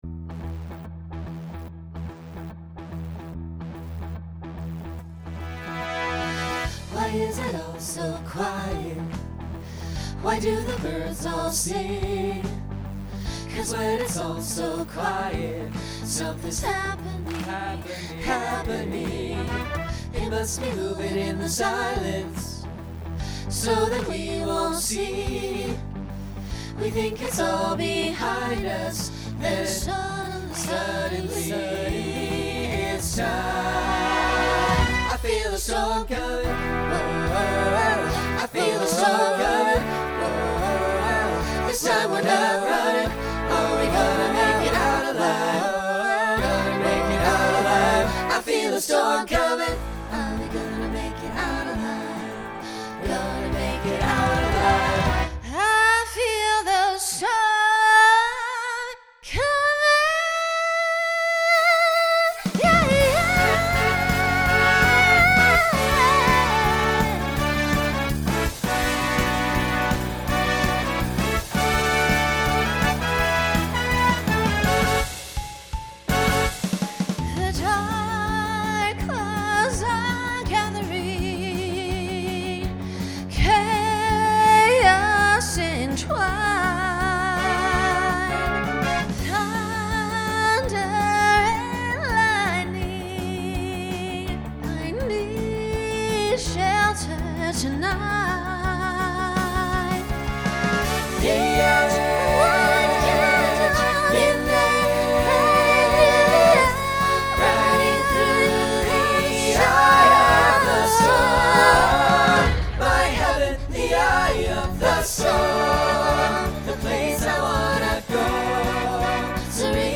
Genre Rock Instrumental combo
Solo Feature Voicing SATB